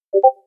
Discord Notification Sound Effect Free Download
Discord Notification